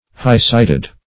High-sighted \High"-sight`ed\, a.